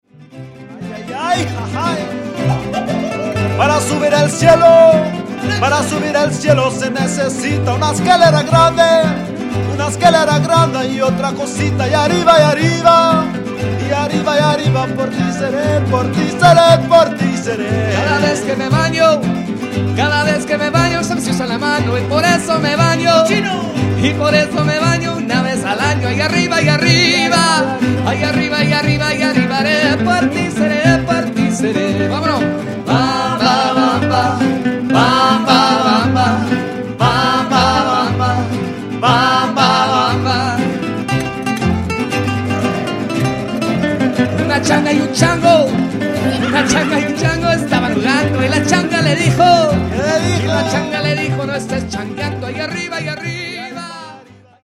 They are, a seasoned group of musicians, perform with a passion and fresh perspective the traditional music of the Mariachi and Trio Romantico.